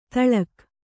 ♪ taḷak